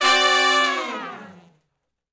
014 Long Falloff (D) har.wav